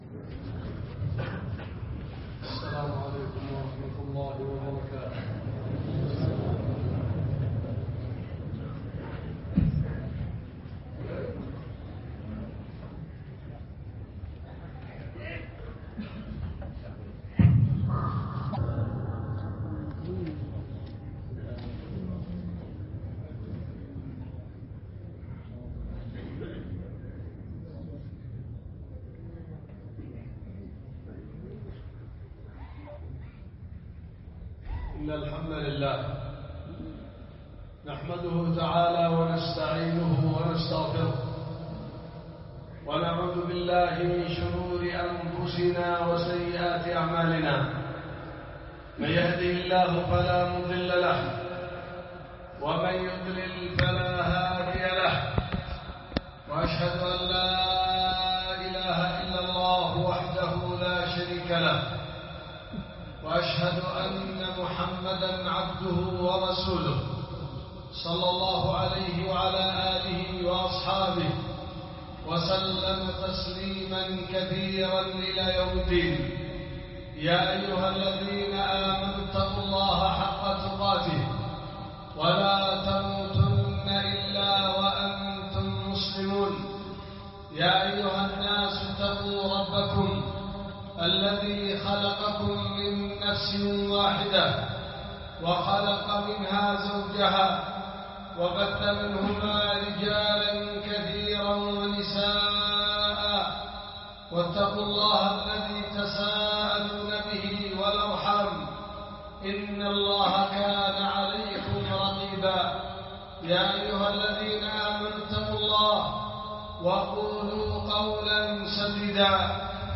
خطبة ألقيت في 10 ربيع الآخر 1444 هجرية في مسجد الخير بصنعاء – اليمن